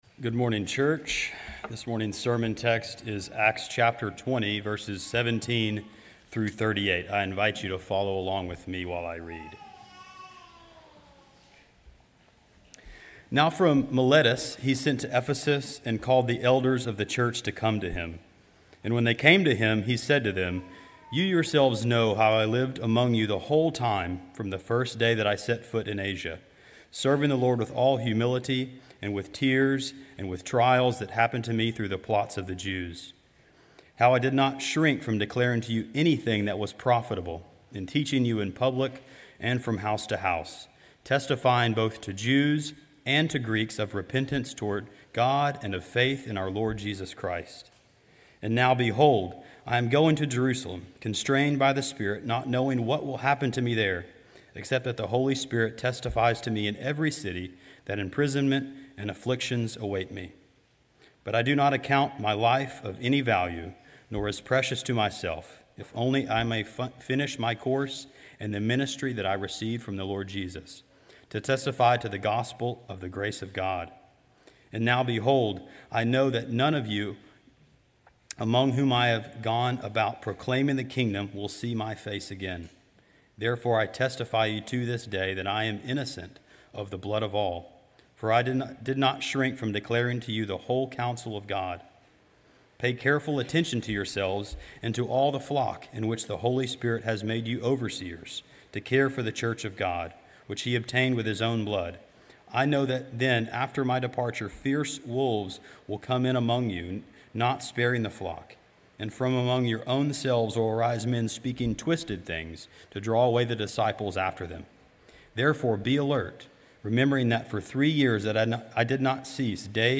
sermon7.20.25.mp3